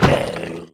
Minecraft Version Minecraft Version snapshot Latest Release | Latest Snapshot snapshot / assets / minecraft / sounds / entity / shulker / death3.ogg Compare With Compare With Latest Release | Latest Snapshot